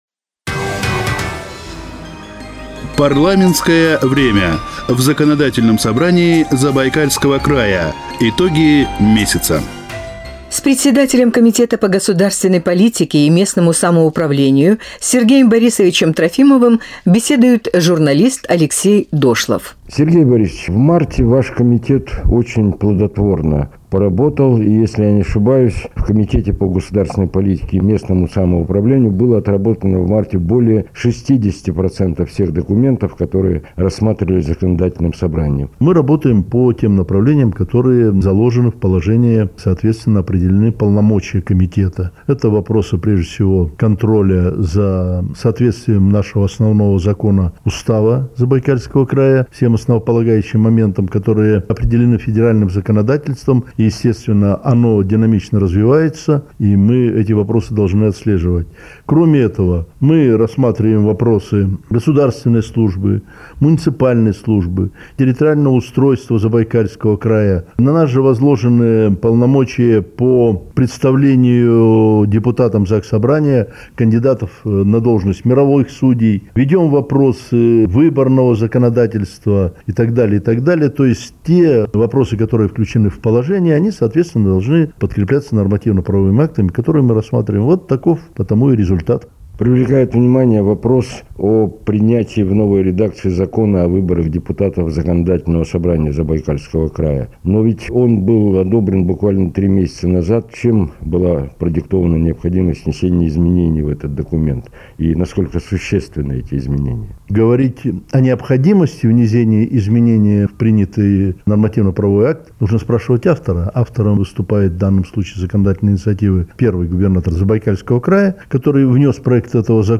Председатель комитета по государственной политике и местному самоуправлению Сергей Трофимов комментирует главные итоги апреля в передаче "Парламентское время. Итоги месяца" (ГТРК-Чита)